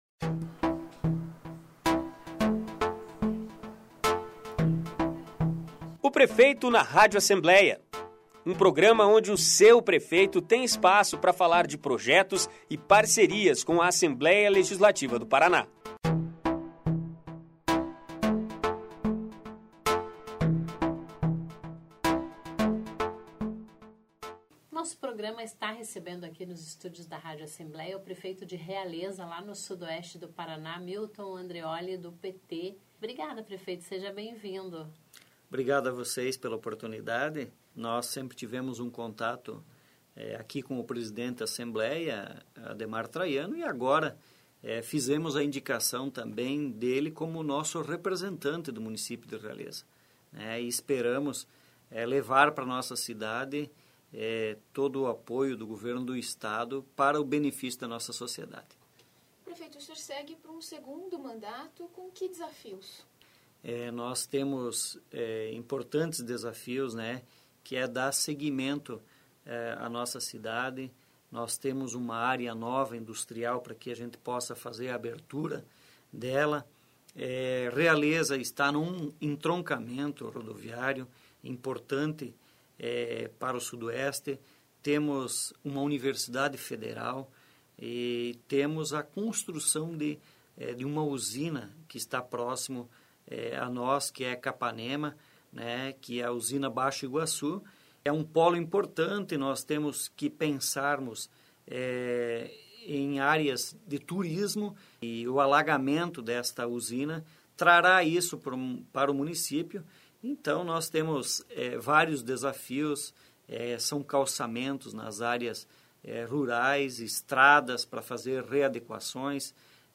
Prefeito de Realeza é o entrevistado do "Prefeito na Rádio Alep" desta semana
Ouça a íntegra da entrevista com Milton Andreolli (PT).